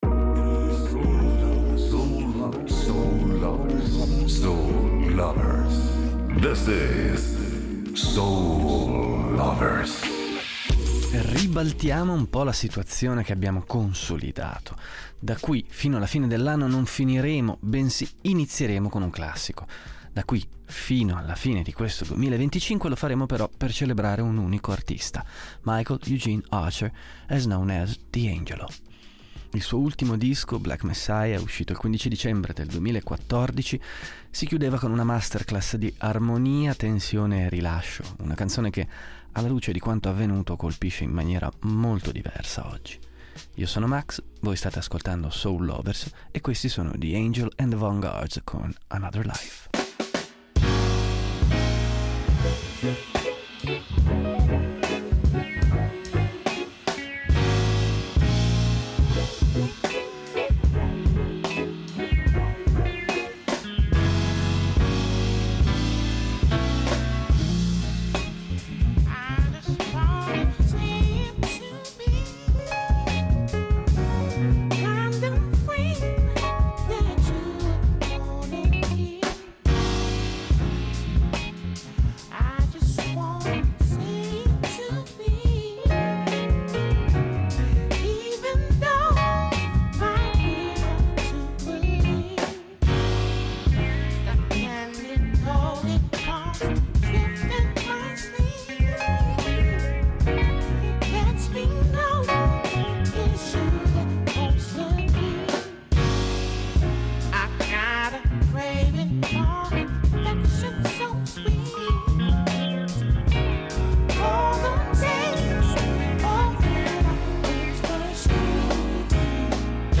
Dal dancefloor alle slowdance, ballads e affini, questo il mood che dominerà il resto della puntata fatta di novità, anticipazioni e un confine sempre più opaco tra due generi che sono proprio parenti stretti stretti.